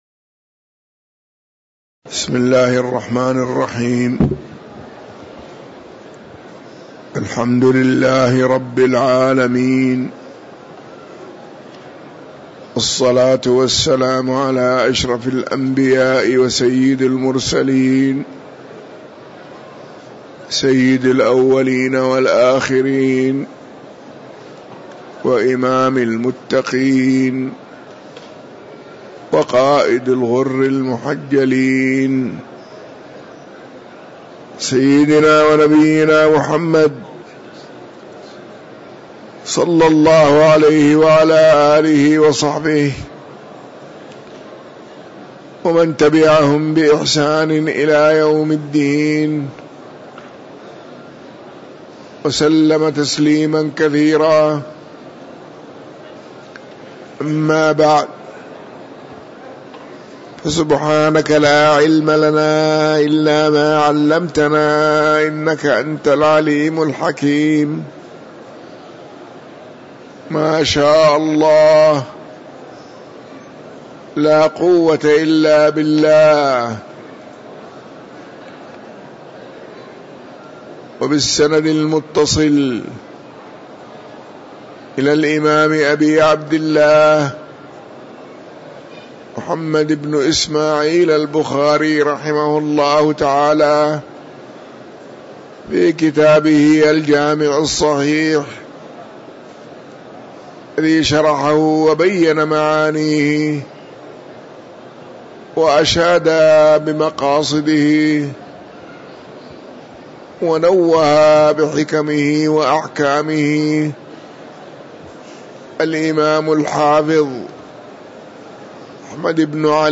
تاريخ النشر ١٦ ربيع الثاني ١٤٤٥ هـ المكان: المسجد النبوي الشيخ